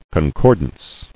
[con·cor·dance]